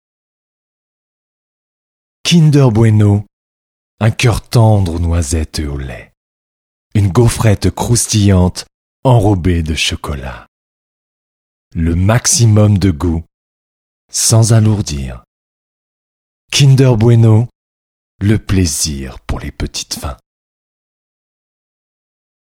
Test Voix off pub kinder
30 - 60 ans - Baryton-basse